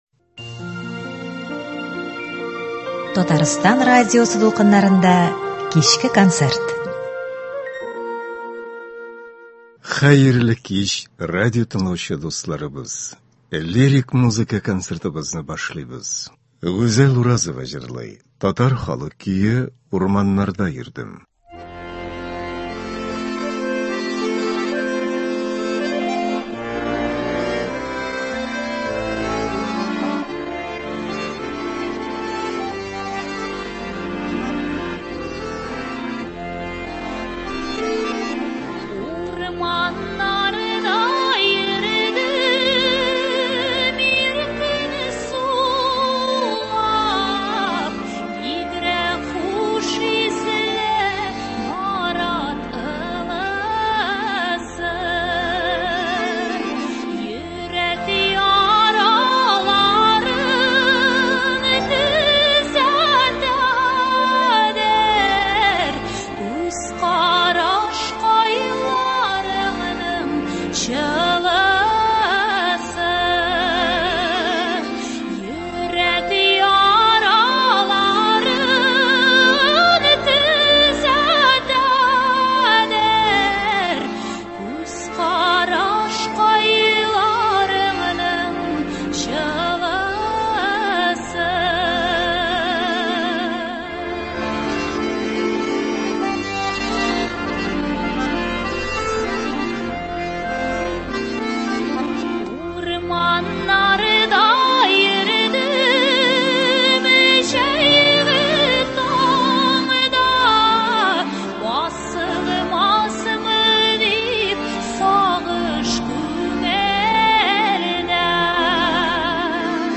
Лирик концерт.